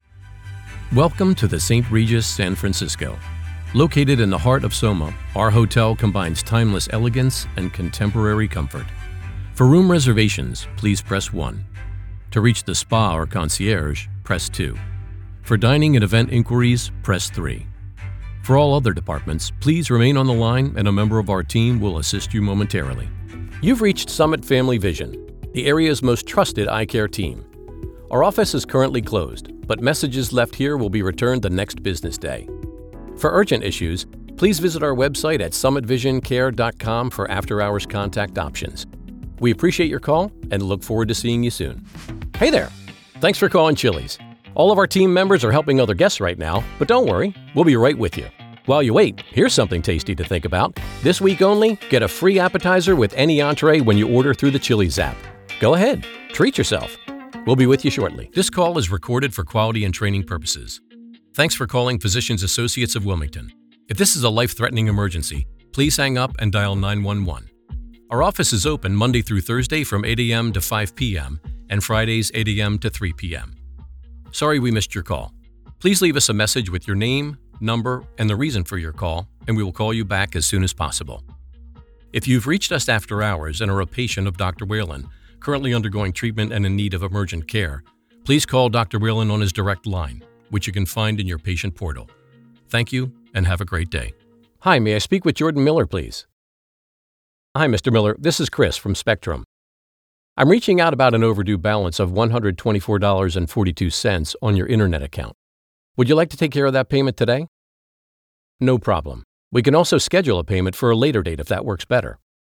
Telephony
• Sennheiser MKH416
• Professional Recording Booth
• Authentic, Approachable, Conversational and Friendly